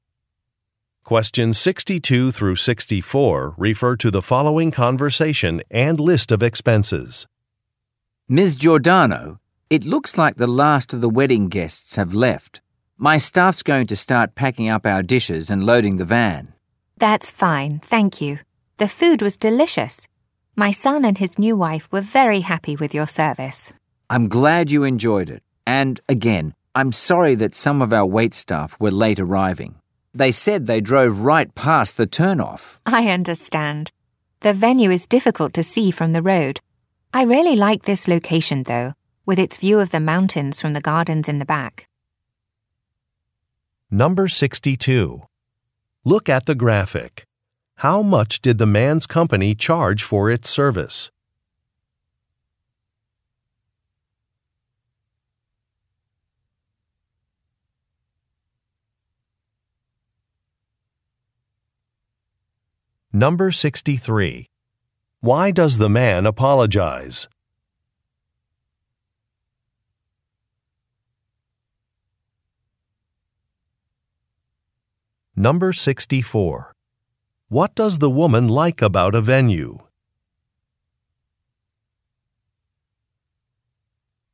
Question 62 - 64 refer to following conversation: